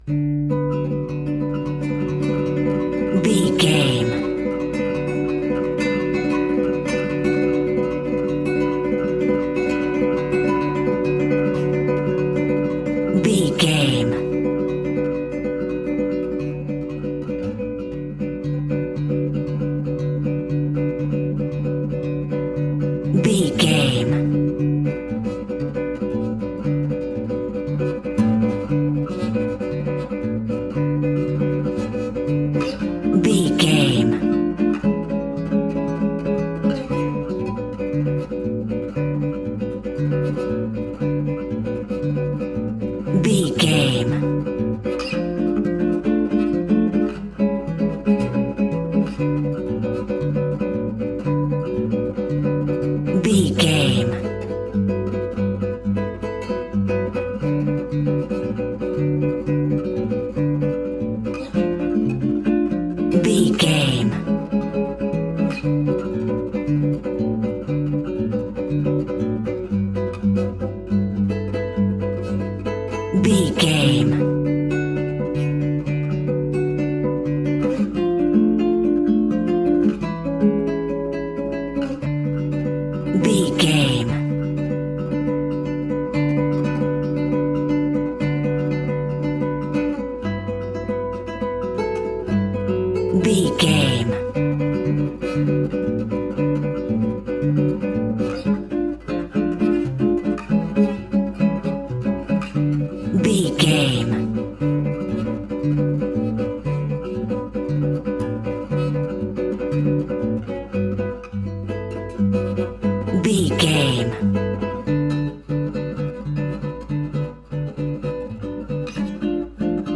Aeolian/Minor
romantic
maracas
percussion spanish guitar